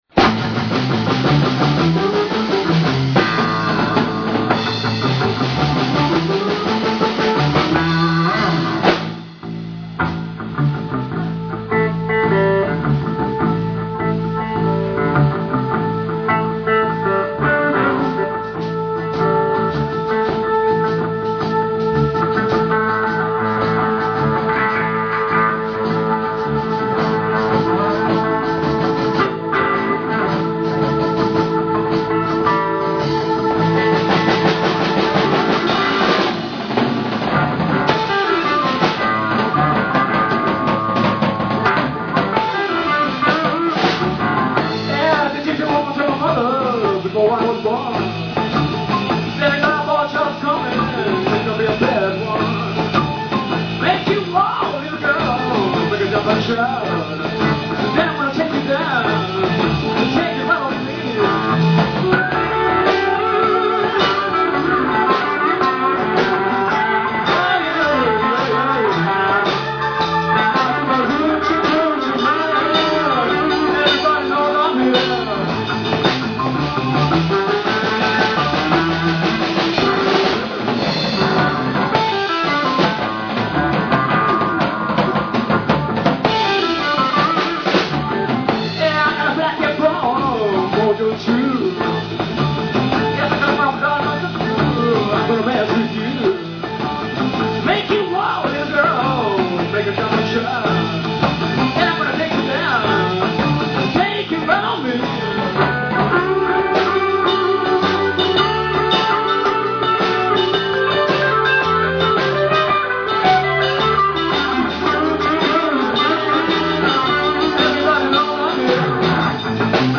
live recording
同じくBBBのライブです。